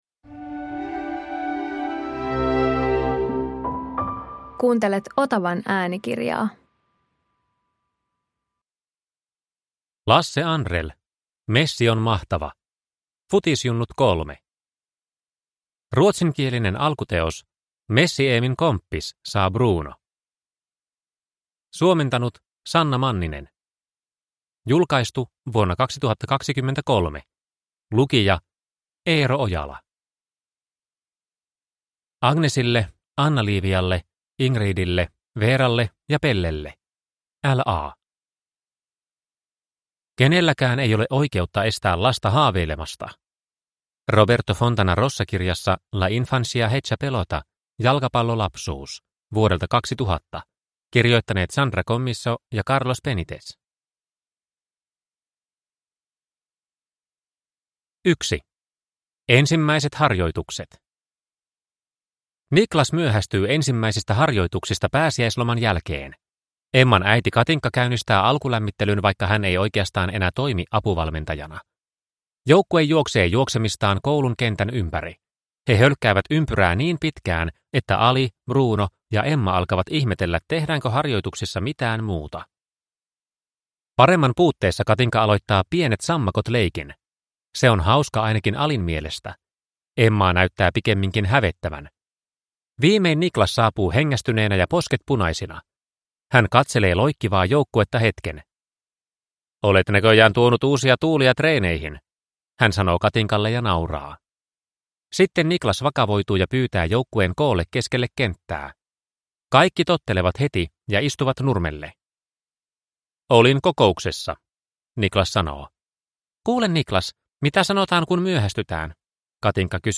Messi on mahtava – Ljudbok – Laddas ner